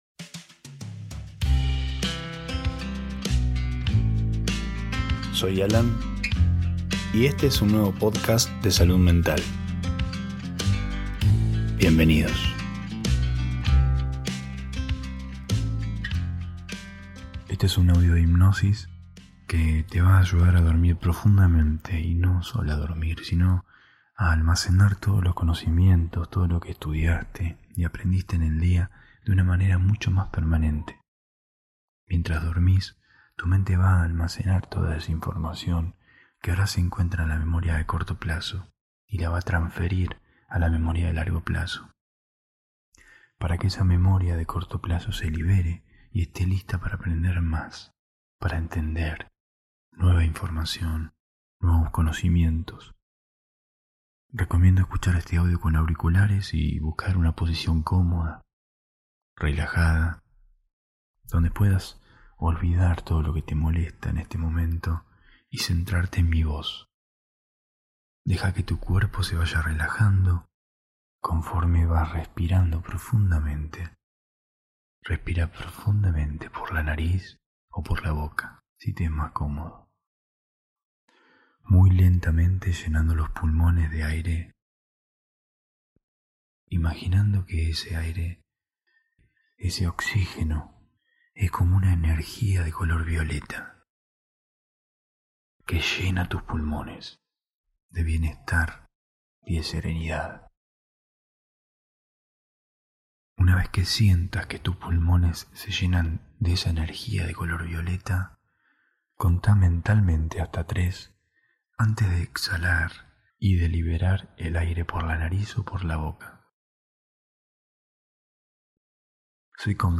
Hipnosis para dormir después de estudiar